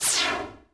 sear.wav